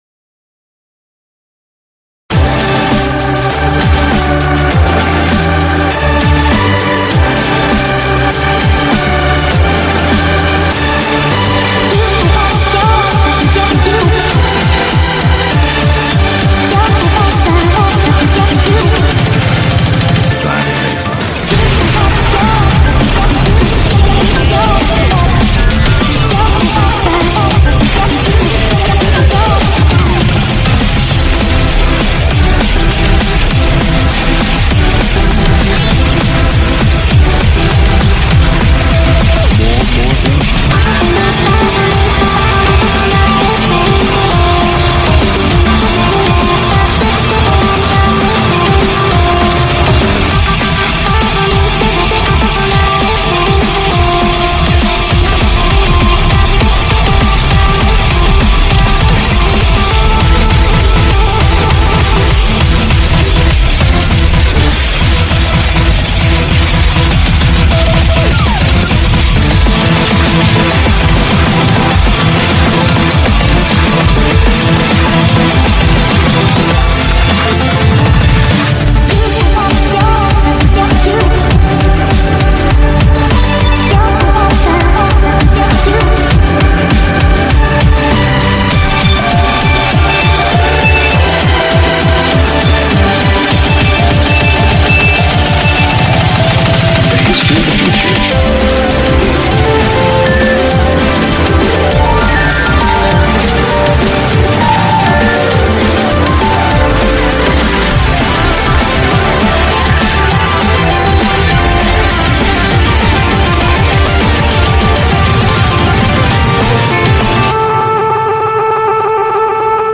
BPM100-200
Audio QualityPerfect (High Quality)
Mashup